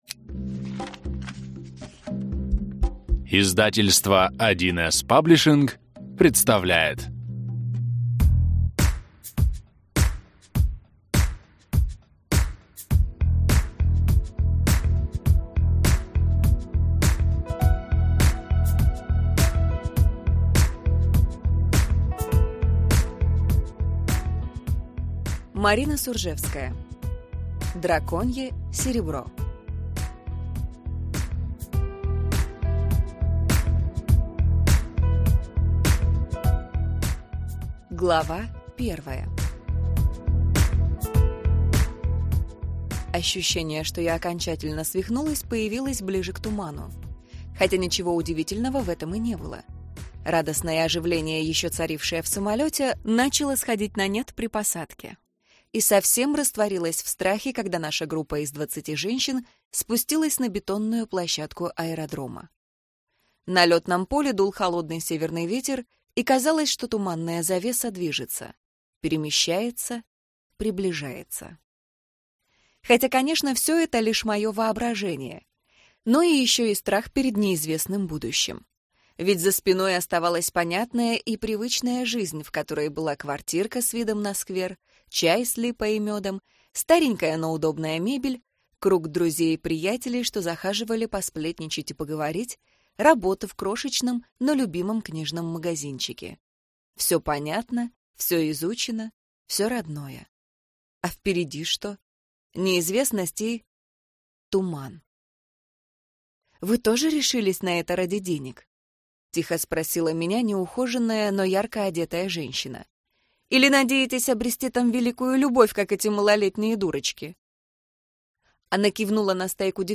Аудиокнига Драконье серебро - купить, скачать и слушать онлайн | КнигоПоиск